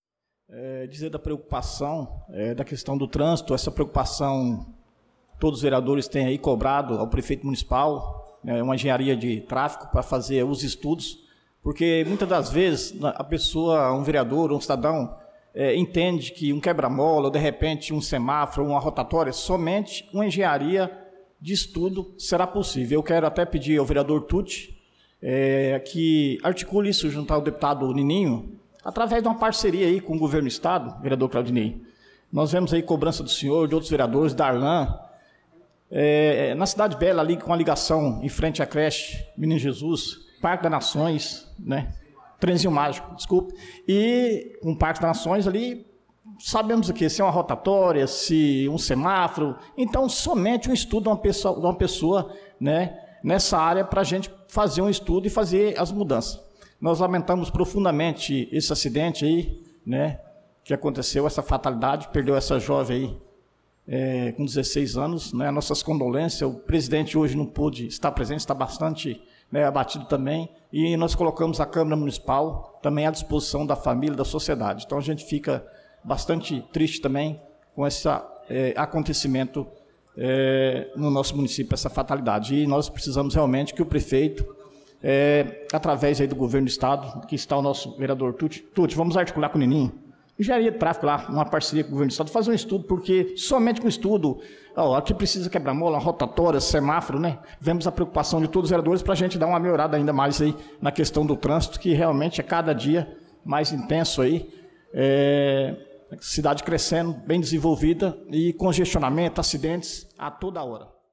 Pronunciamento do vereador Dida Pires na Sessão Ordinária do dia 23/06/2025.